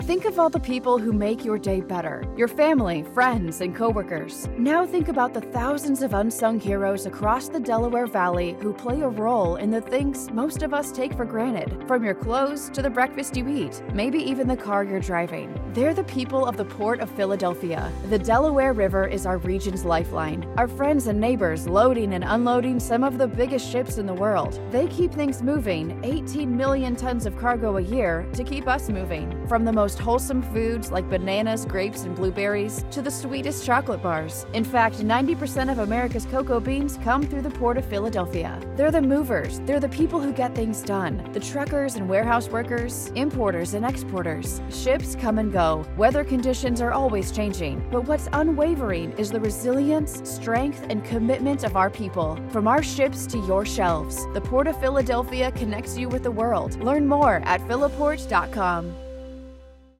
RADIO: As heard on
LISTEN! Radio Spot #1